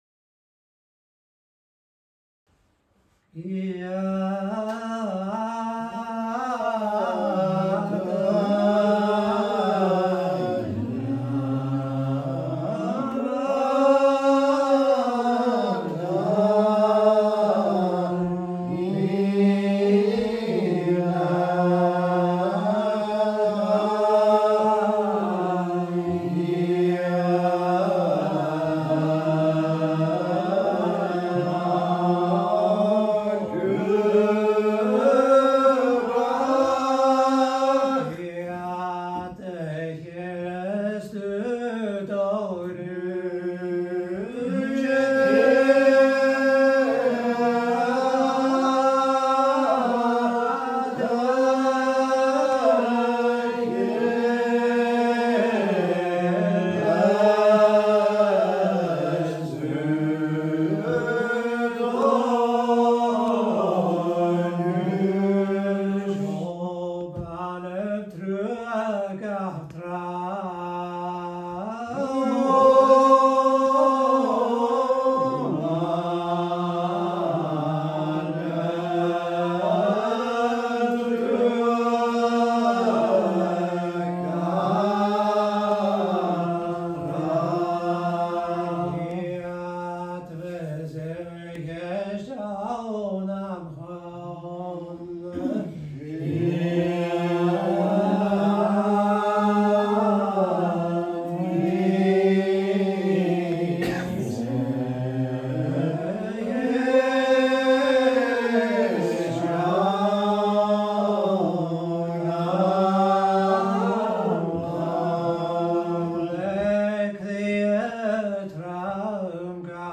A’ Seinn nan Sailm Gaelic Psalmody